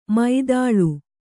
♪ mādāḷu